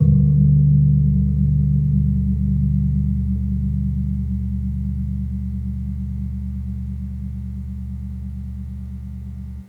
Gamelan Sound Bank
Gong-C1-p.wav